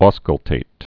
skəl-tāt)